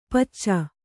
♪ pacca